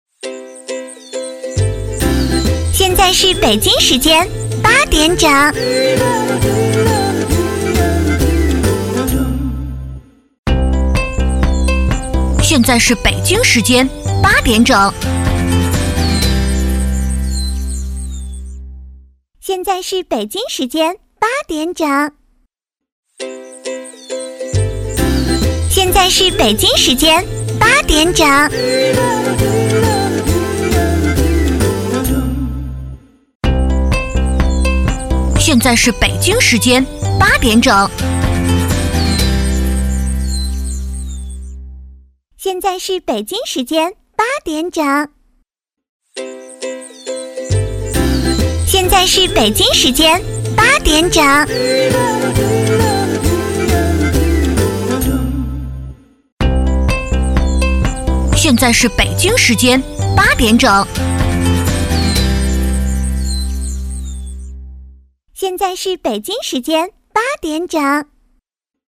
国语青年低沉 、神秘性感 、调性走心 、亲切甜美 、感人煽情 、素人 、女微电影旁白/内心独白 、100元/分钟女S143 国语 女声 微电影旁白-纯朴小镇-旁白-自然 低沉|神秘性感|调性走心|亲切甜美|感人煽情|素人